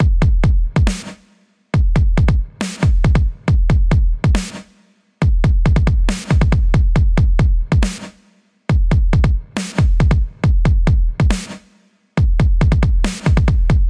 网络Dubstep节拍138BPM
描述：为最近的一个项目创建的杜比斯特节奏模式，以138BPM录制。
标签： 138 bpm Dubstep Loops Drum Loops 2.34 MB wav Key : Unknown
声道立体声